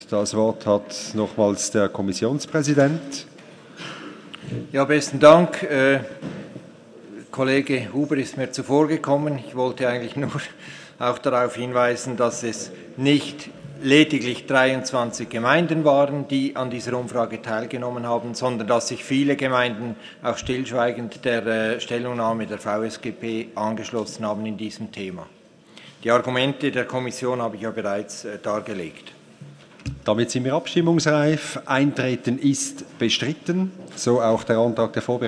Session des Kantonsrates vom 20. und 21. Februar 2017